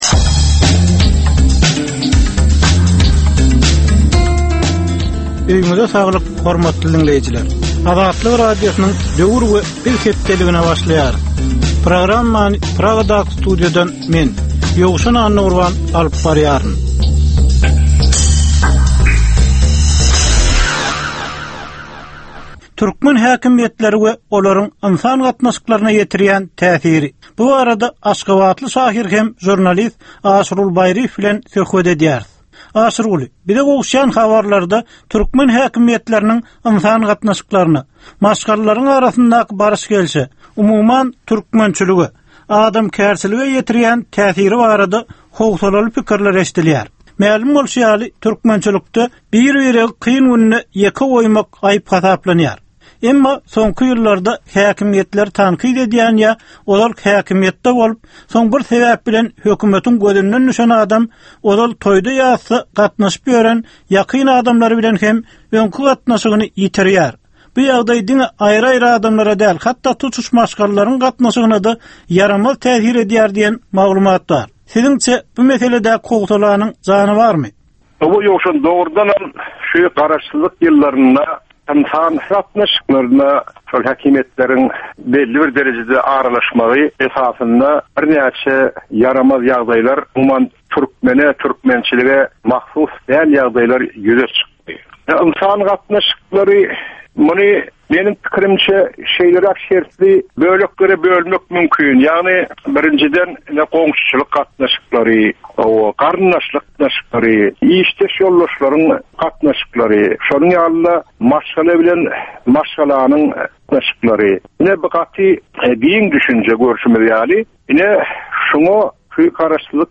Türkmen jemgyýetindäki döwrün meseleleri. Döwrün anyk bir meselesi barada 10 minutlyk ýörite syn-geplesik. Bu geplesikde dinleýjiler, synçylar we bilermenler döwrün anyk bir meselesi barada pikir öwürýärler, öz garaýyslaryny we tekliplerini orta atýarlar.